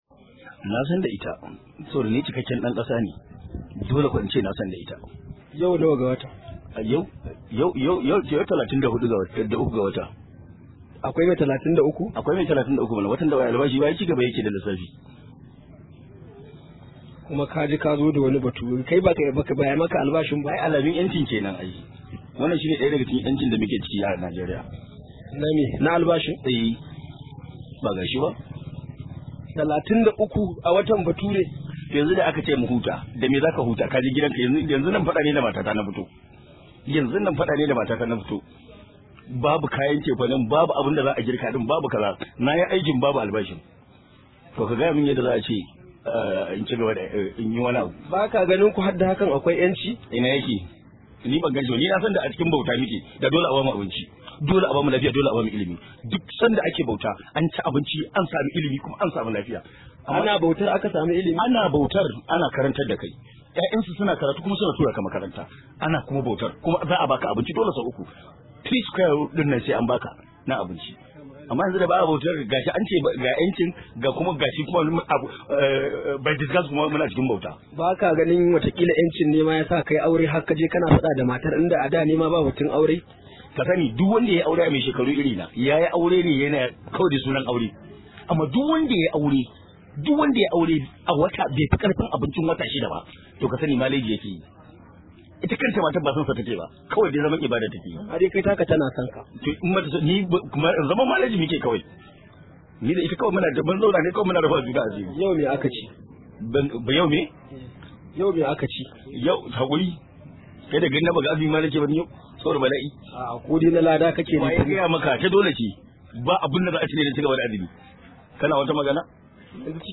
Rahoto: Duk wanda ya yi aure a wannan lokacin ba shi da abincin wata 6 maleji yake yi – Magidanci